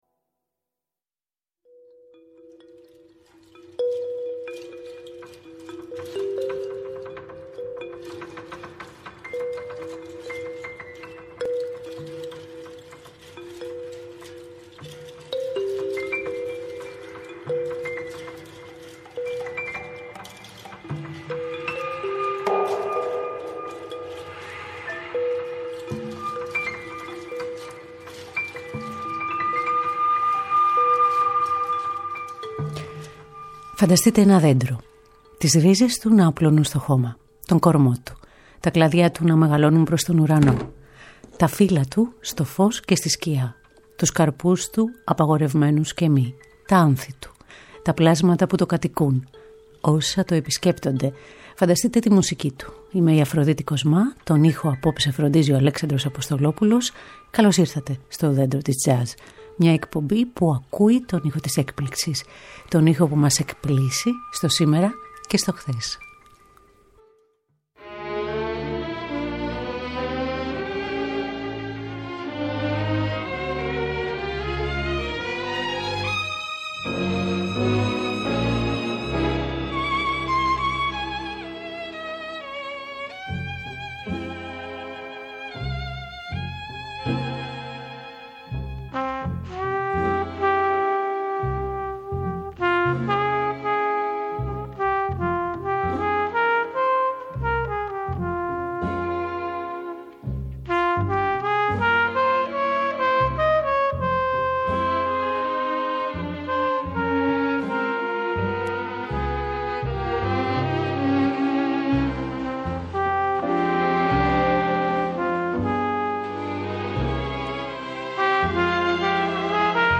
Μουσική Τζαζ